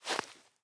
Minecraft Version Minecraft Version snapshot Latest Release | Latest Snapshot snapshot / assets / minecraft / sounds / block / powder_snow / step7.ogg Compare With Compare With Latest Release | Latest Snapshot
step7.ogg